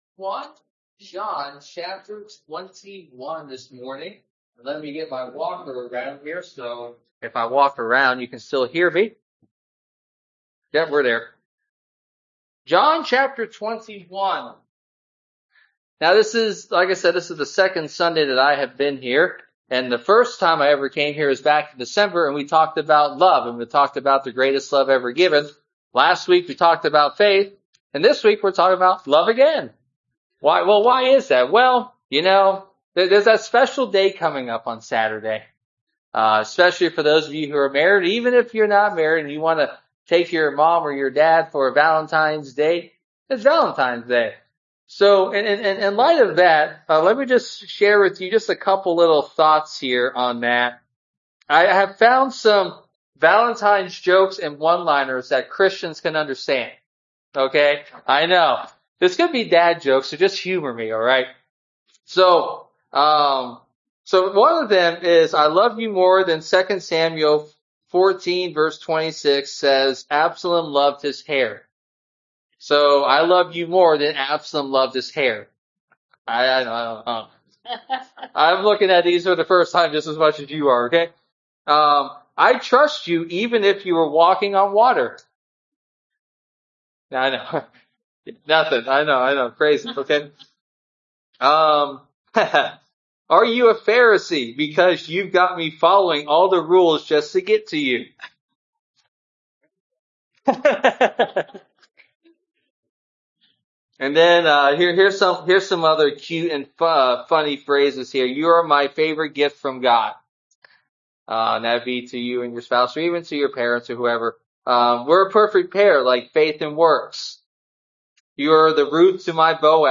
Sermon-February-8-2026.mp3